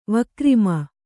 ♪ vakrima